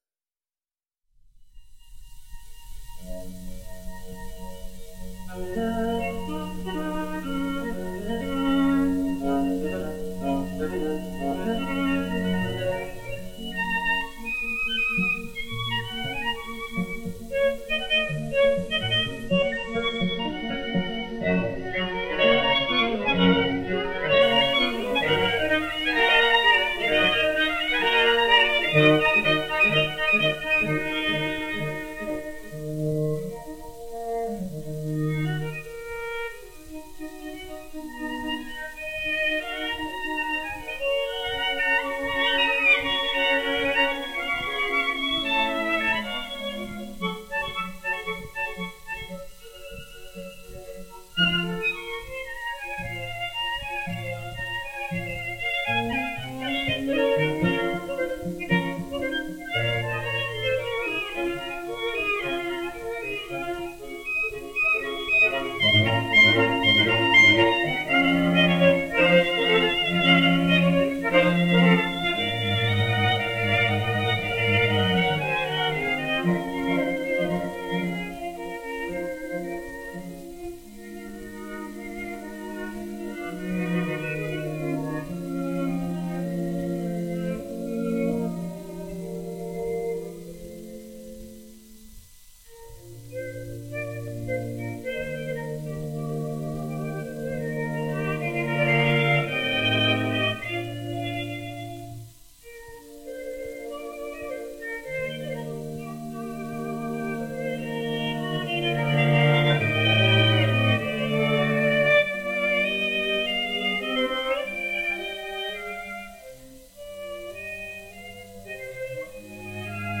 This 78rpm record set contains three 12" 78rpm records.
string quartet